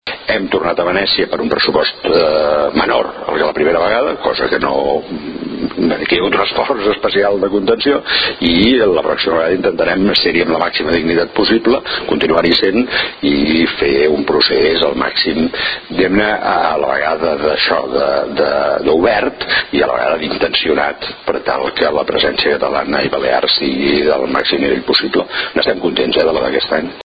Àudio: El director de l'IRL, Vicenç Villatoro, destaca que la institució mantindrà la seva participació a la Biennal de Venècia